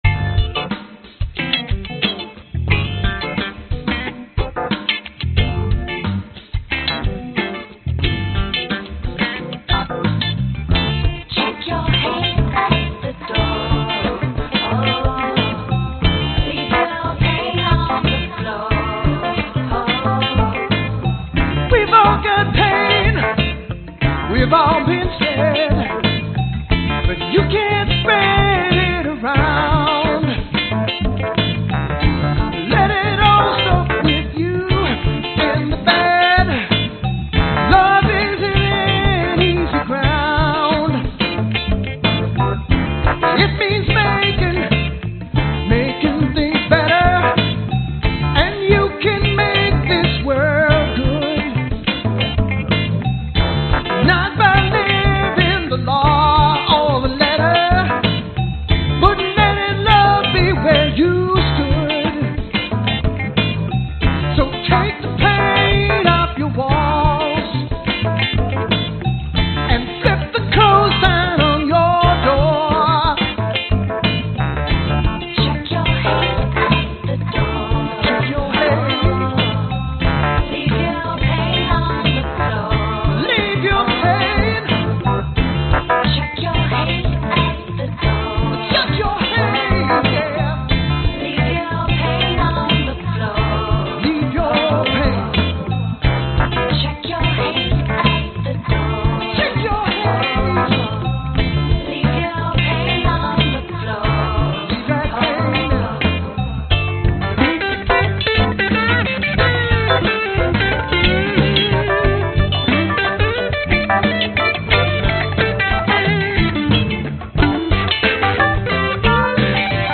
Tag: 贝斯 女声 长笛 吉他 男声 合成器